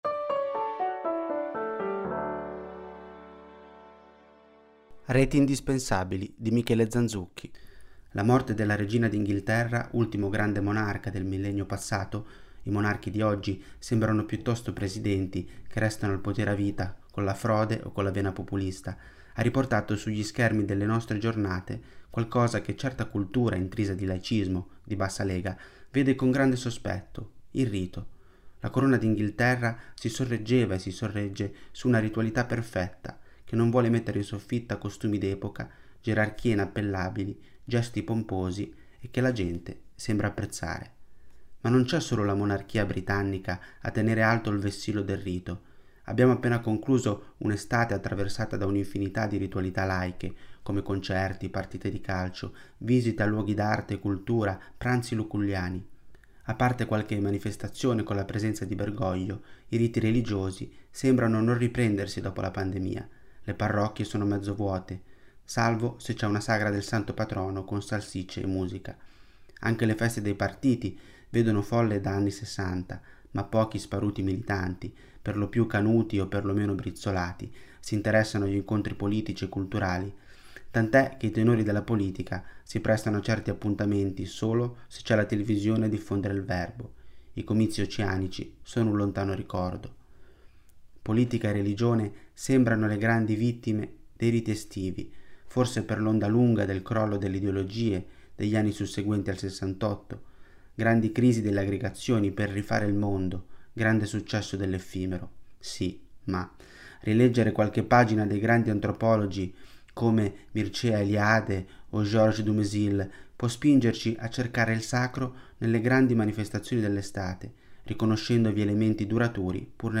Per ogni numero, ci sarà una selezione di 10 articoli letti dai nostri autori e collaboratori.
Al microfono, i nostri redattori e i nostri collaboratori.